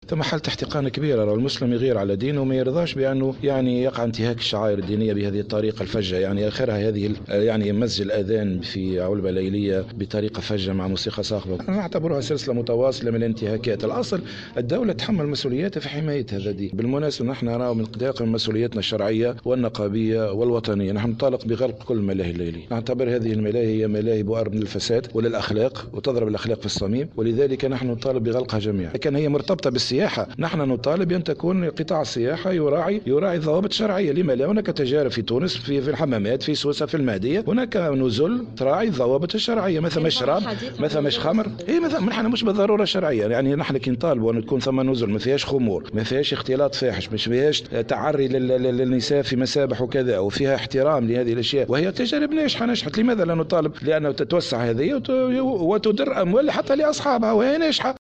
intervention sur les ondes de Jawhara FM